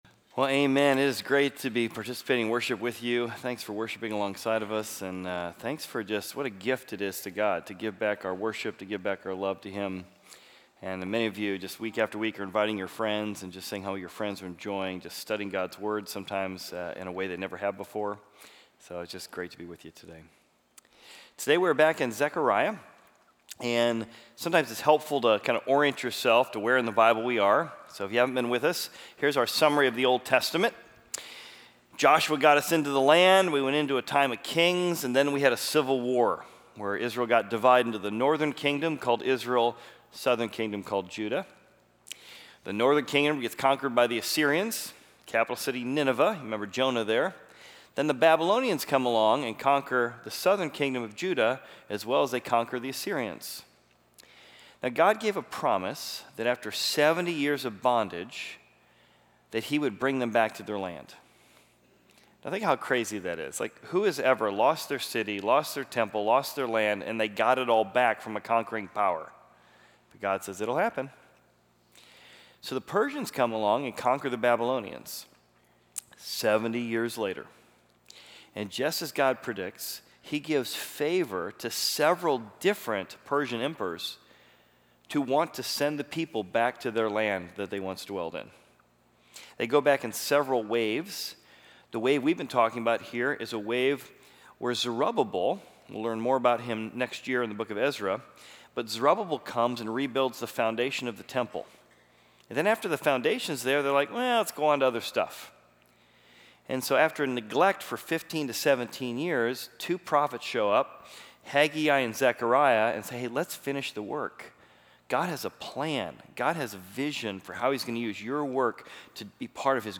Equipping Service / Zechariah: Hope-Ray Vision / How to Find Future Joy